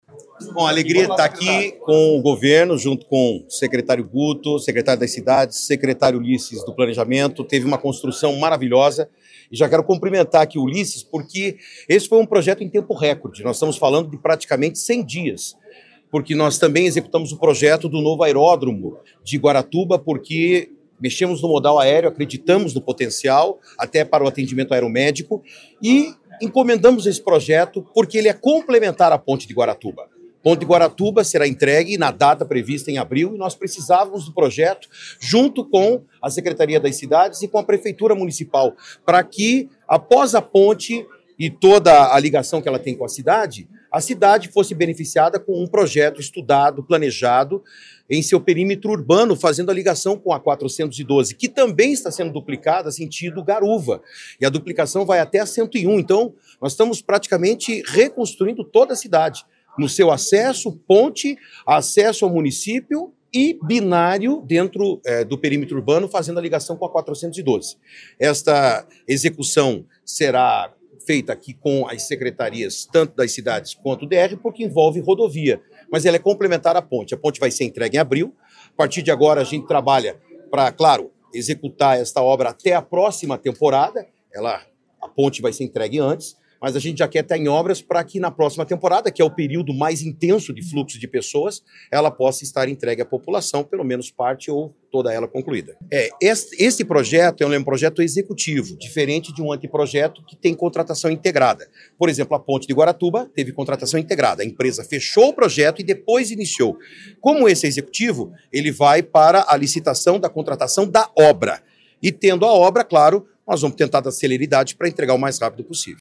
Sonora do secretário Estadual da Infraestrutura e Logística, Sandro Alex, sobre o novo binário de Guaratuba